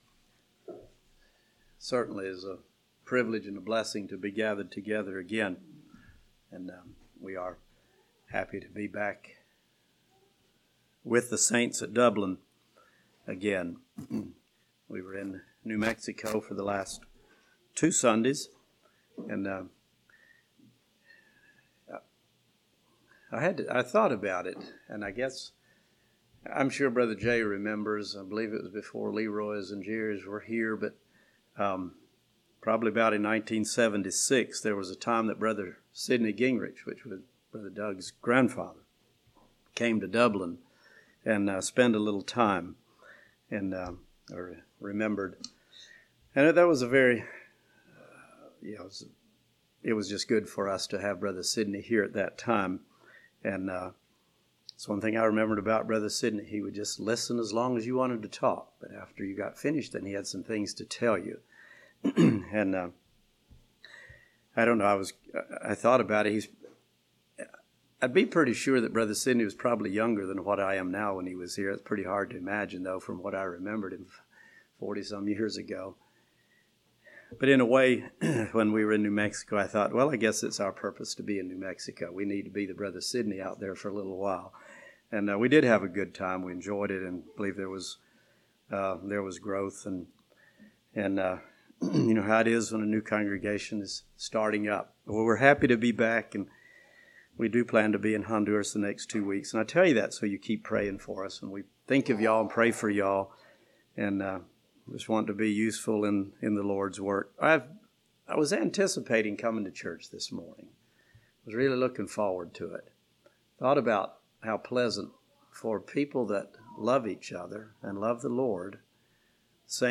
Sermons 02.25.18 Play Now Download to Device Gods Promises Congregation